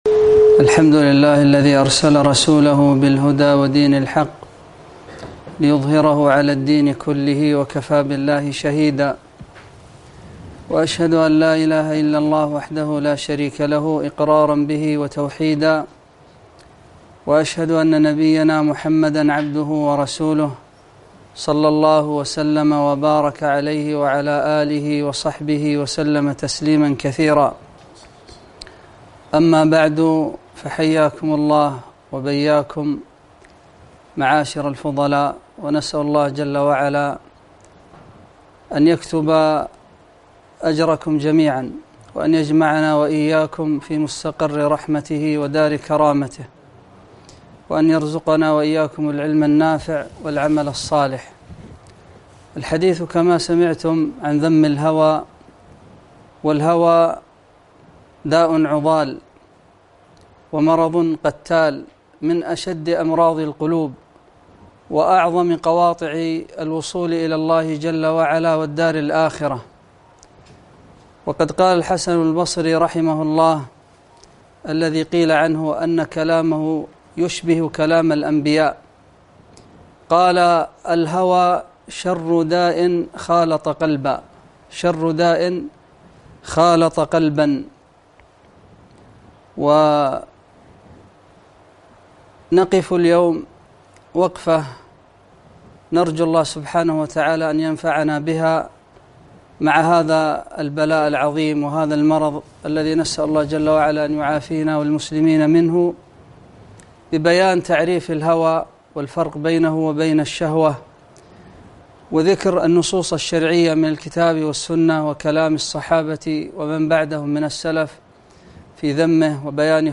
محاضرة - ذم الهوى